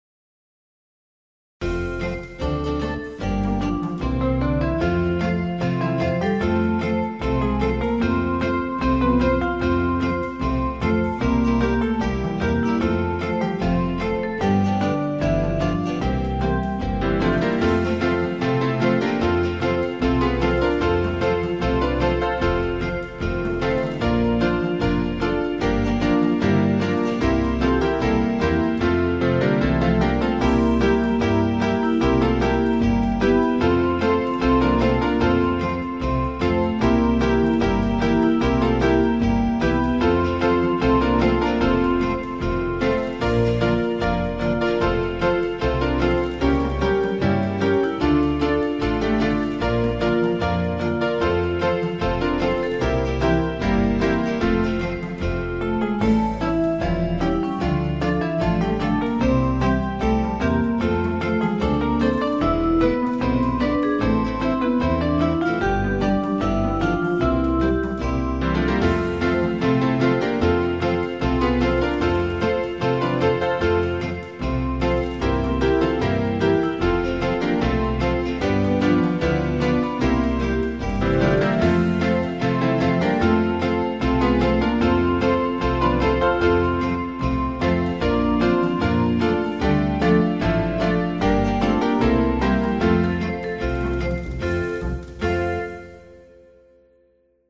8-beat intro. with 3 lead-in notes.
Play Parts 1, 2, 3, 1.